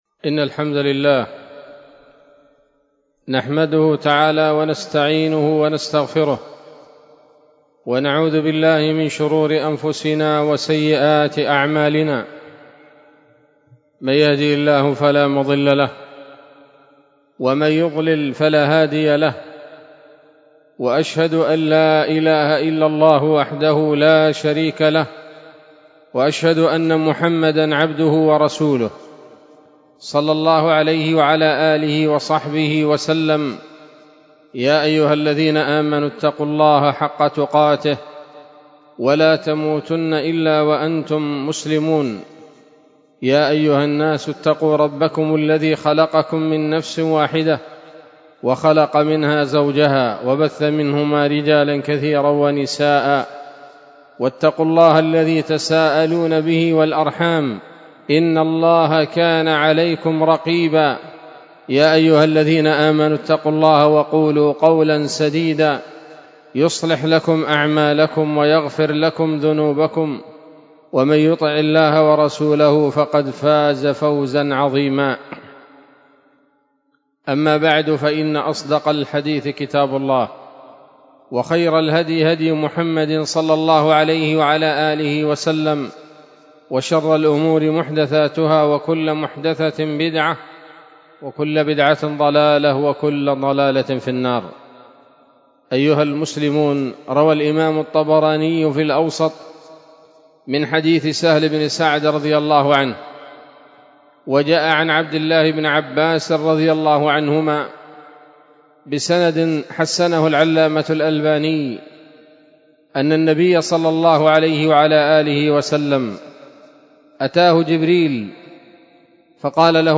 خطبة جمعة بعنوان: (( عزة النفس والاستغناء عن الناس )) 6 جمادى الأولى 1446 هـ، دار الحديث السلفية بصلاح الدين